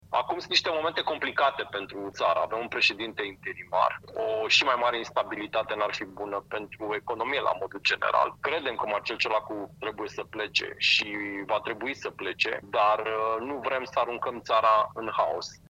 Ionuț Moșteanu, liderul deputaților USR: „Credem că Marcel Ciolacu trebuie să plece și va trebui să plece, dar nu vrem să aruncăm țara în haos”